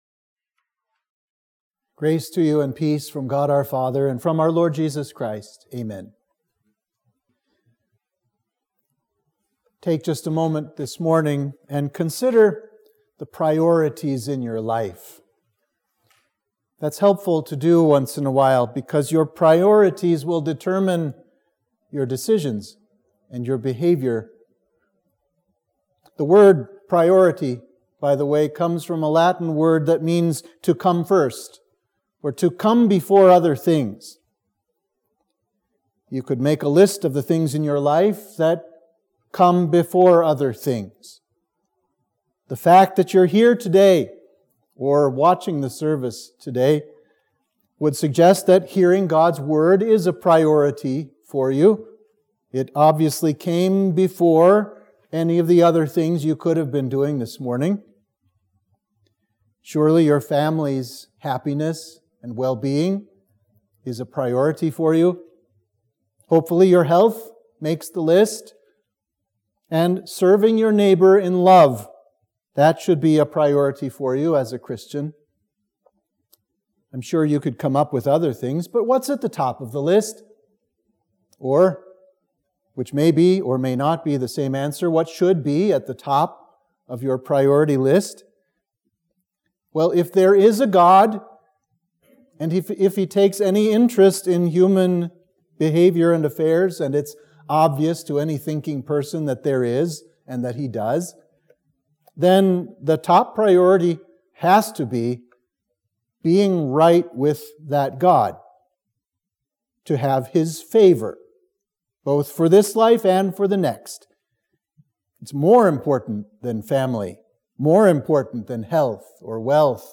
Sermon for Trinity 11